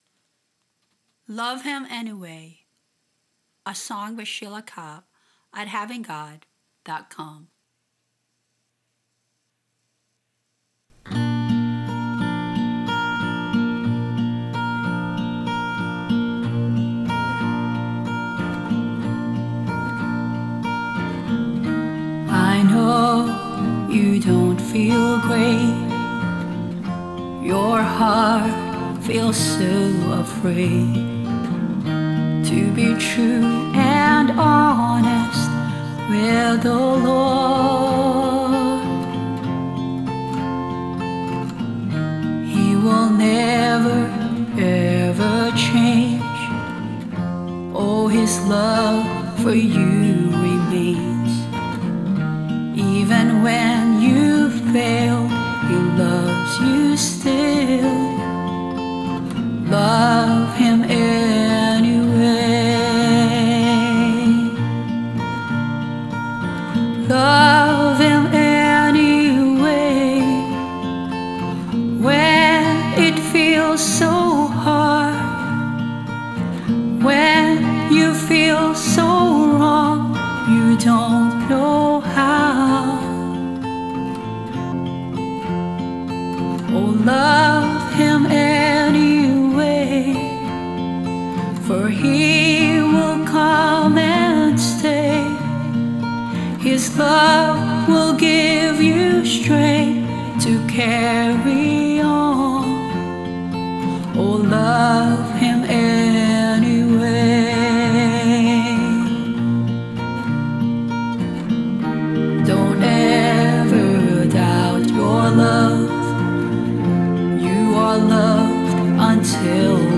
Vocals, guitar and bass
Strings and keyboard